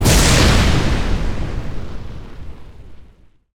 ZombieSkill_SFX
sfx_skill 11_1.wav